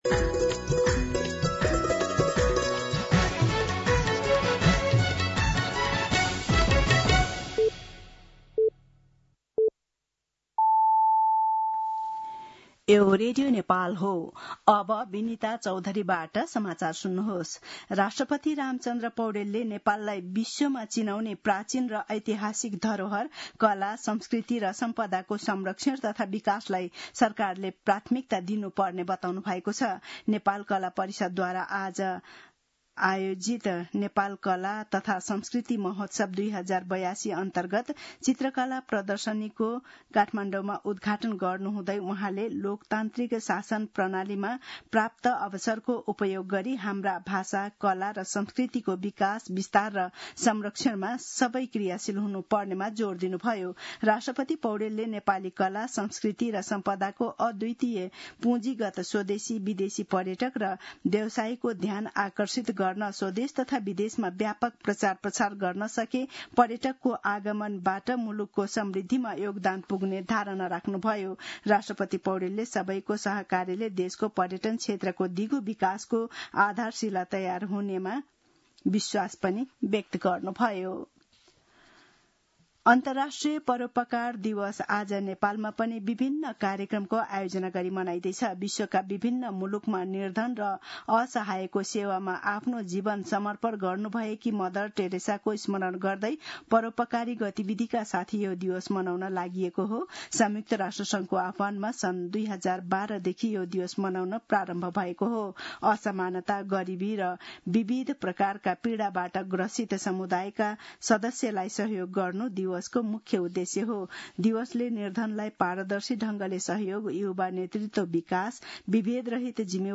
दिउँसो १ बजेको नेपाली समाचार : २० भदौ , २०८२